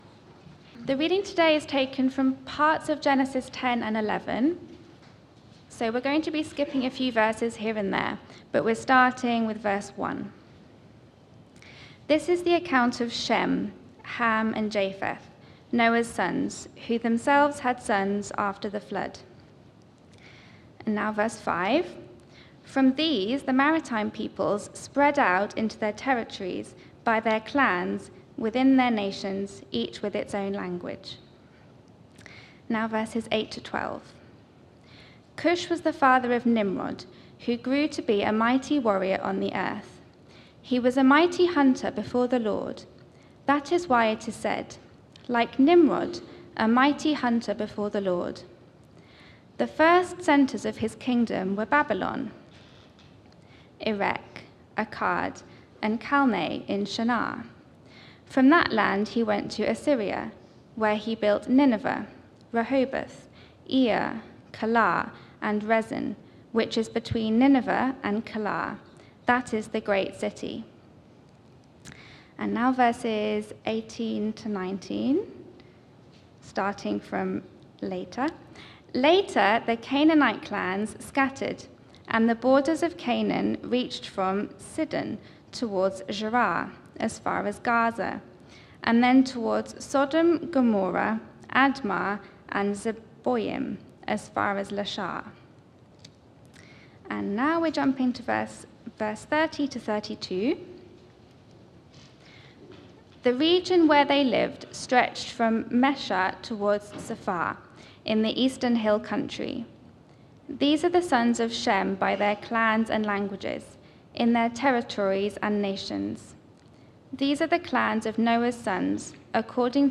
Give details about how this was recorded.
Media for Sunday Service on Sun 16th Jul 2023 10:00